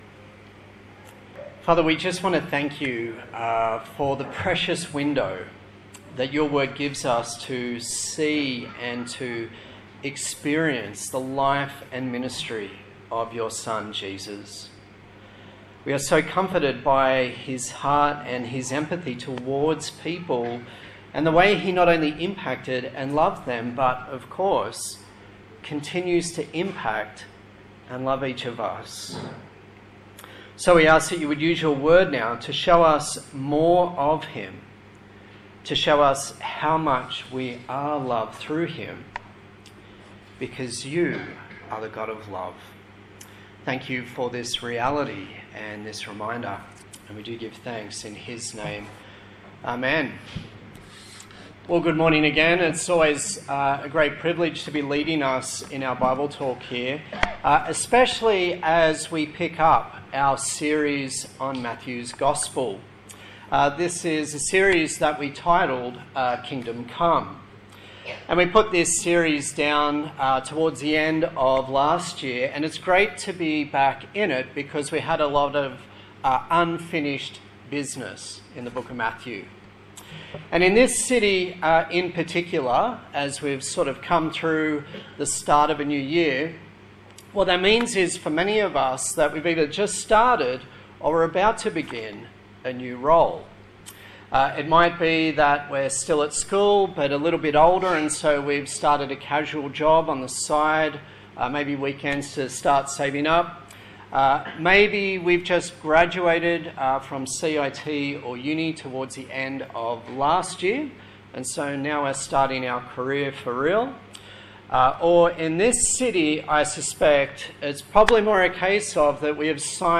Kingdom Come Passage: Matthew 4:12-25 Service Type: Sunday Morning